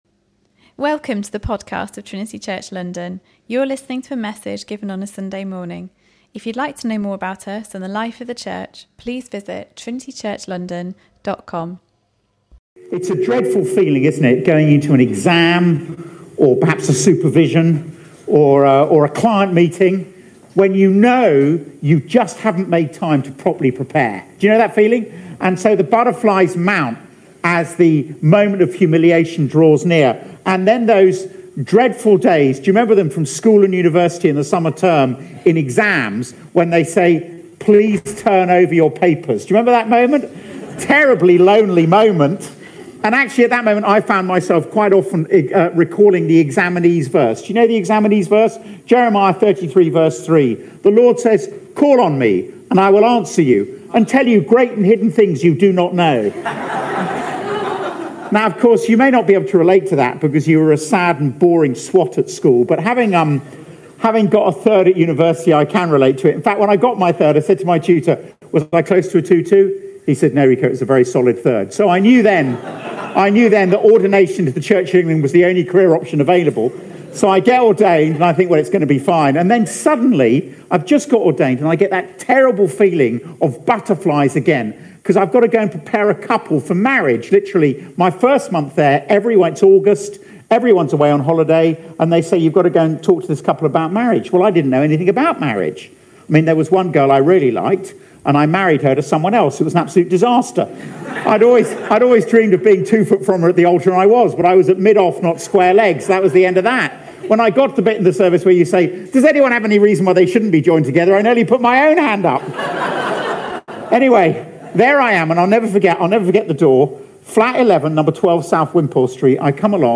If you do not think that Christmas is the best news you've ever heard then you have not understood its meaning. In this sermon